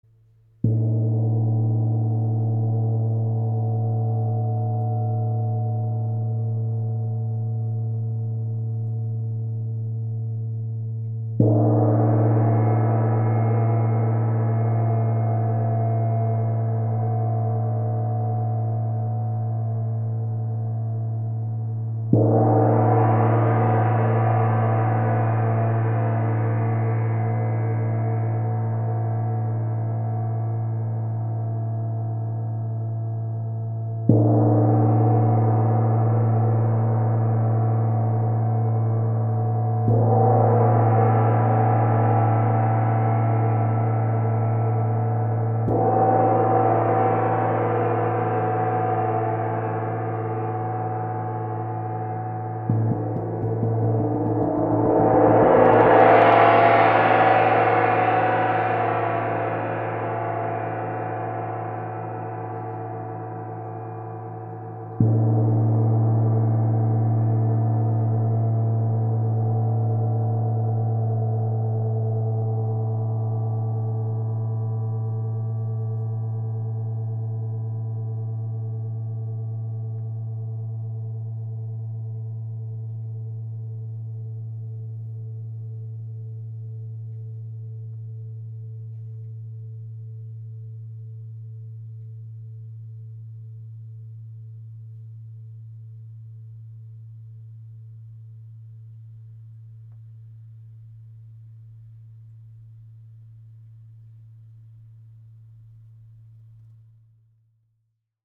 Feng gong (Premium Quality wind gong) - Mandalia Music
Composition : Cuivre 80% et Etain 20%
Feng gong 50cm
feng_50mp3.mp3